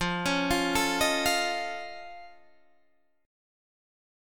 F+7 Chord
Listen to F+7 strummed